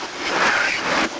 The above EVP was taken in a once in a lifetime investigation into a Murder's house, I liked it so much I wrote a book on it.
This one is a little tougher to make out, only in what is said.